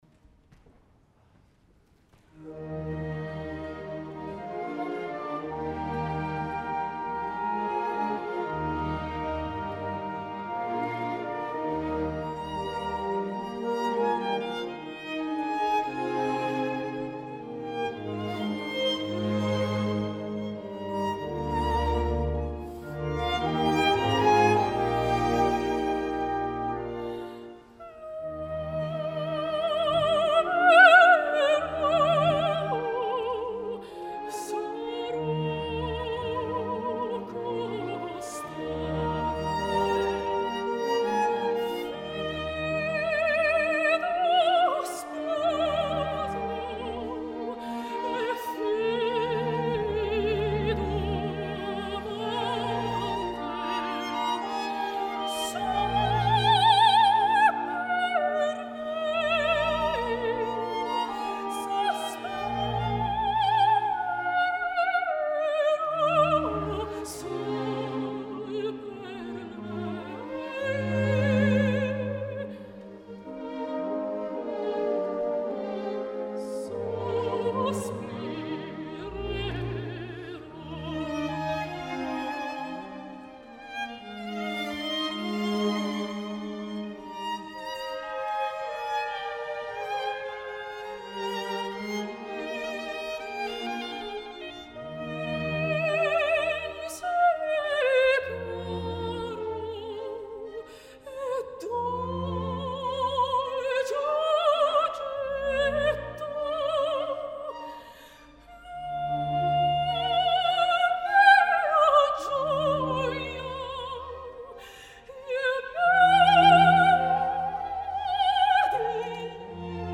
Escoltem ara la famosa ària amb el solo obligat de violí “L’amerò, sarò constante”
Verbier Festival Chamber Orchestra
versió de concert
2 d’agost de 2014 Salle des Combins (Verbier, Suïssa)